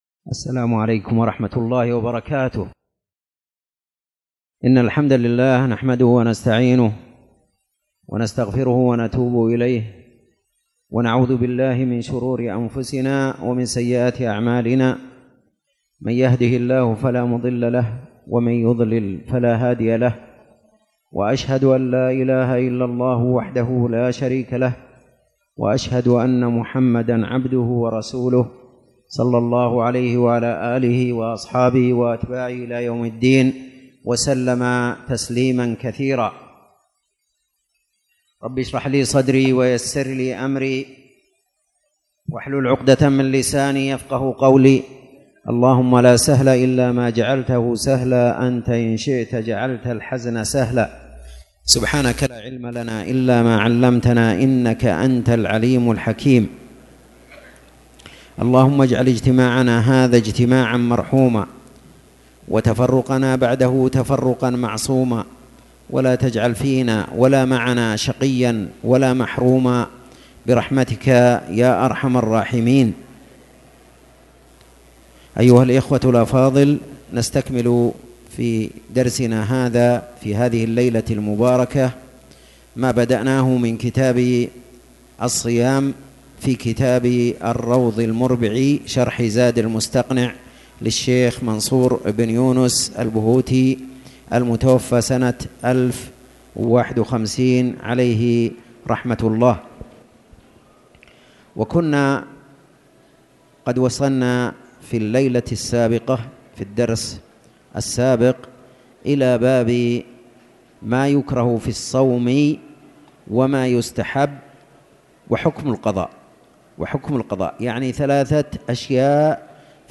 تاريخ النشر ١٢ ربيع الثاني ١٤٣٨ هـ المكان: المسجد الحرام الشيخ